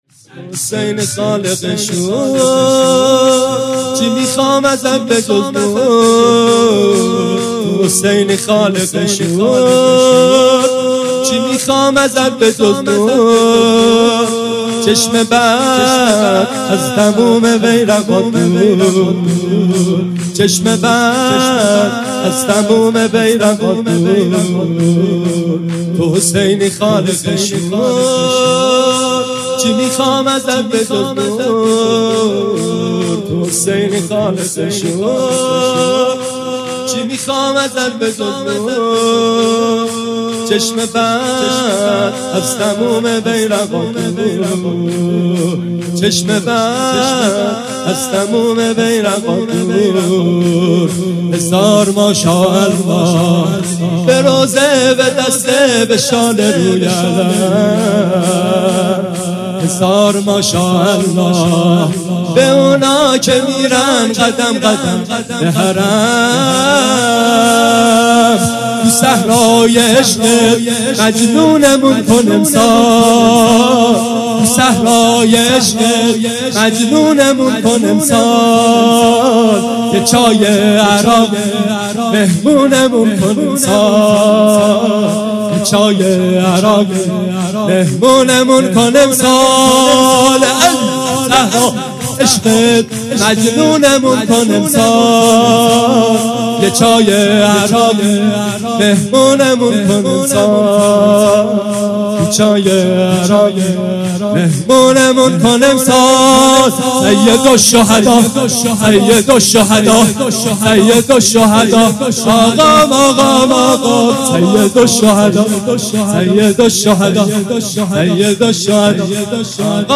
۱۶ مرداد ۴۰۱ روز تاسوعا حضرت ابوالفضل علیه السلام هیئت روضه الشهدا محرم ۴۰۱ اشتراک برای ارسال نظر وارد شوید و یا ثبت نام کنید .